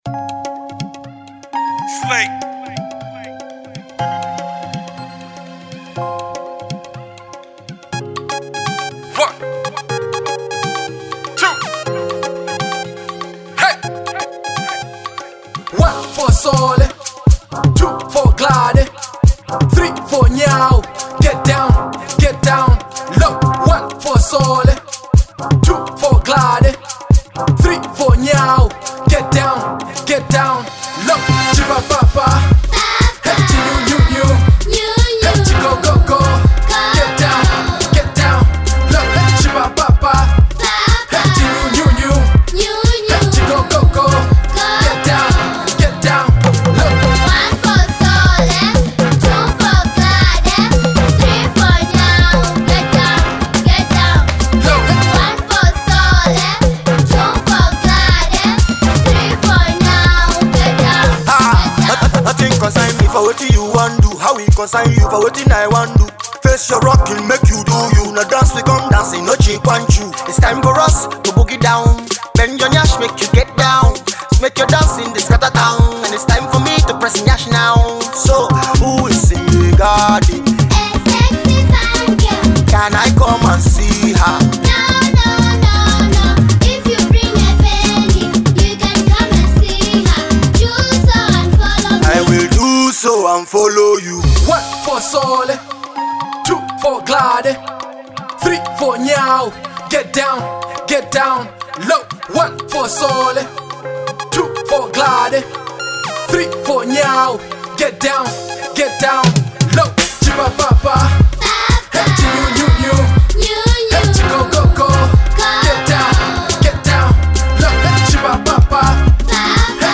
Alternative Rap